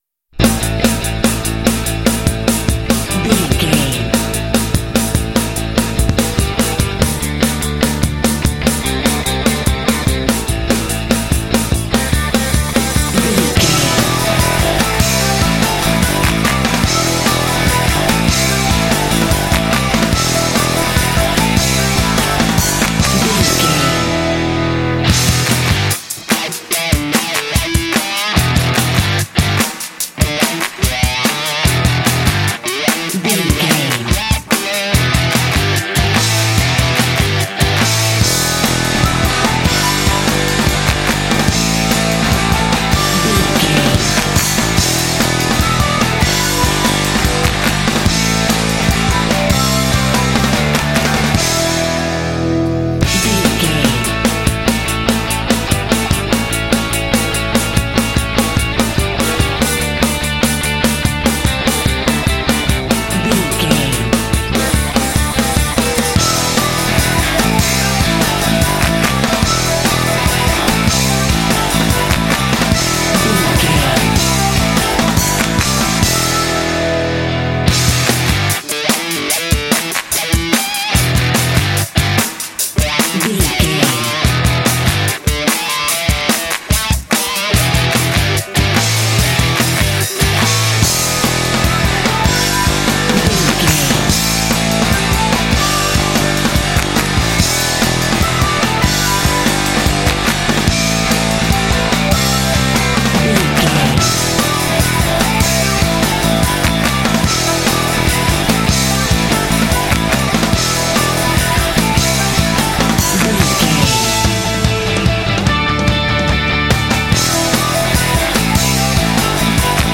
Epic / Action
Aeolian/Minor
powerful
energetic
heavy
electric guitar
bass guitar
drums
heavy metal
classic rock